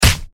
Punch7.wav